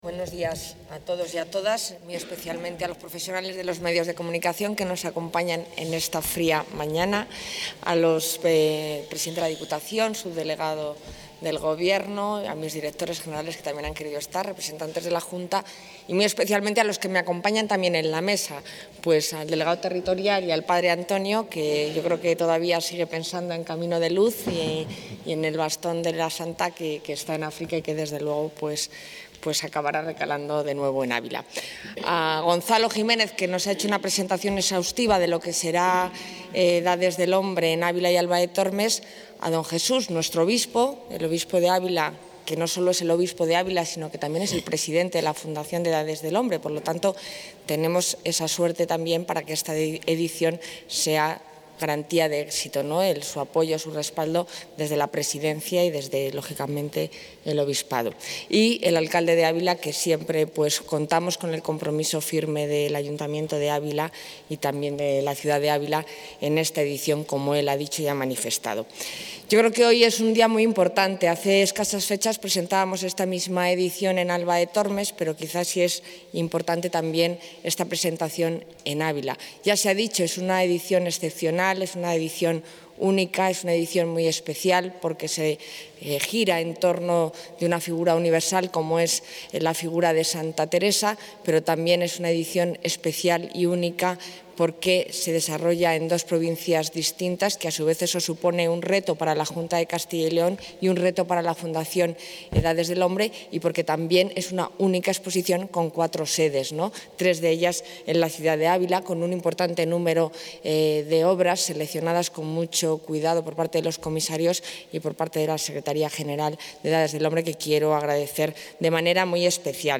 Audio consejera.